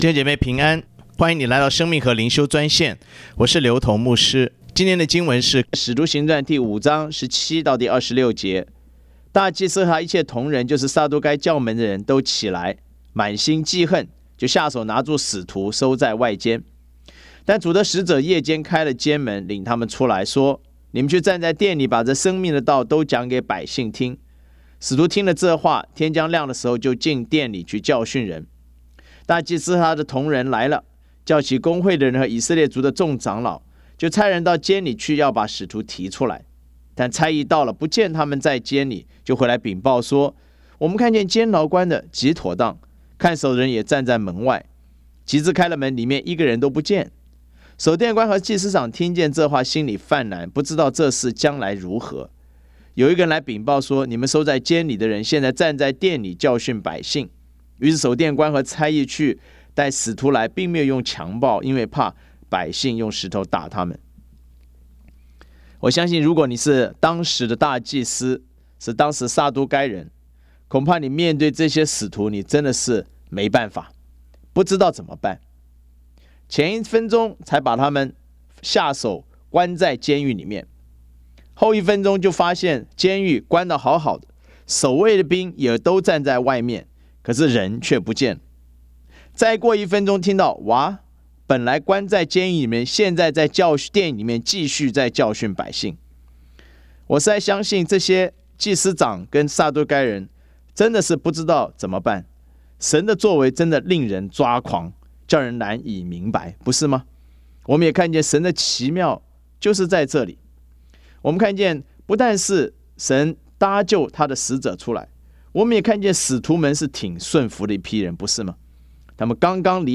藉着每天五分钟电话分享，以生活化的口吻带领信徒逐章逐节读经。